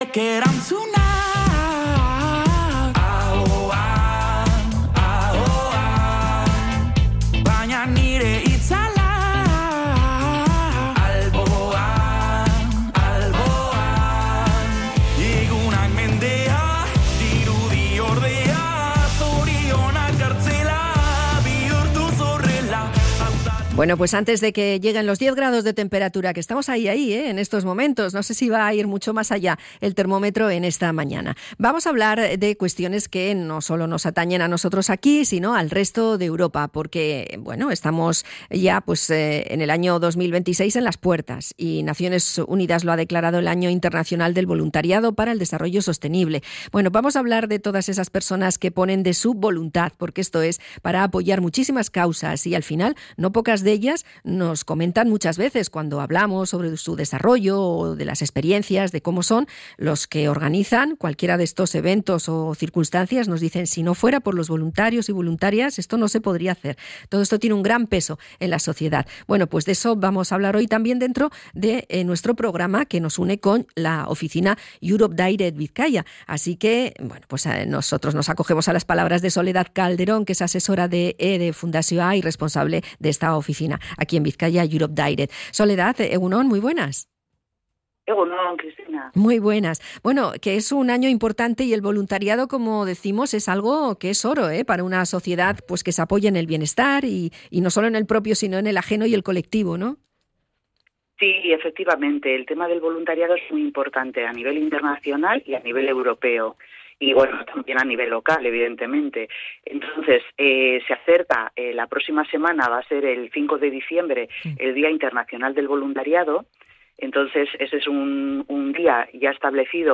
Visita mensual a la radio